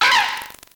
Cri de Furaiglon dans Pokémon Noir et Blanc.